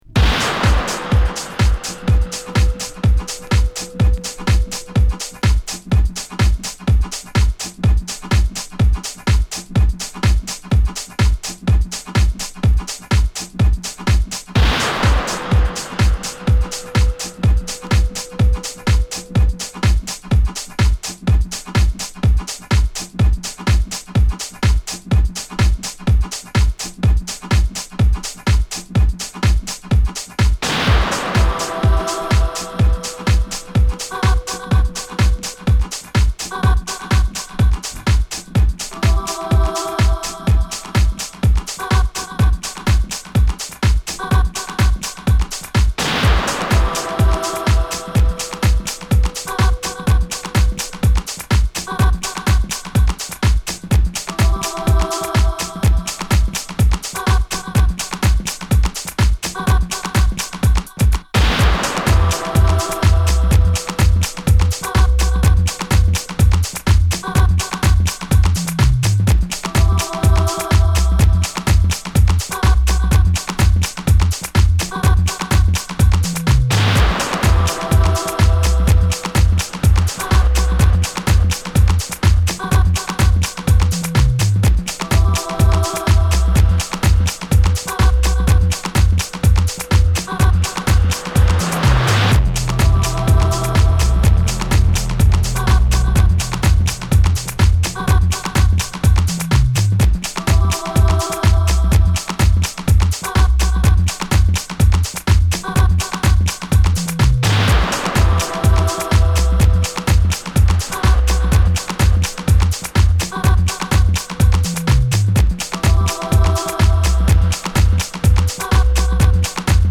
＊試聴はA→B1→B2です